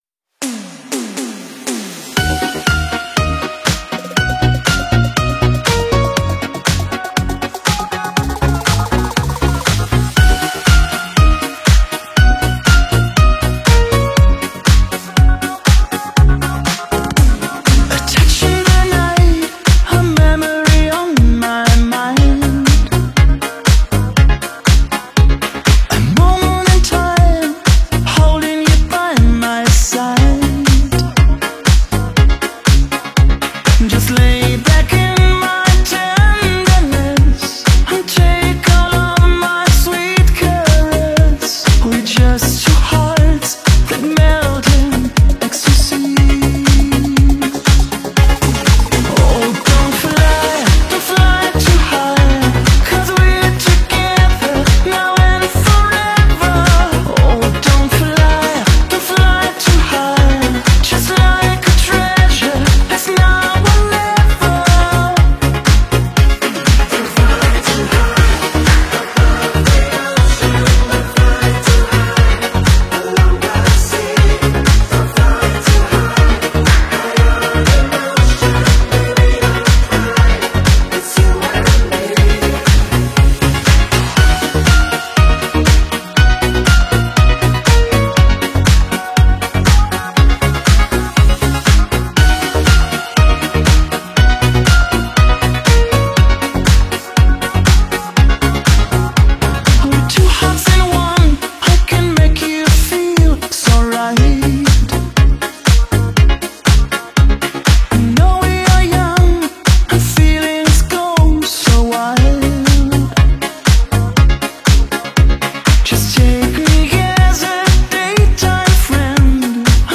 Ретро музыка
музыка в стиле 80-х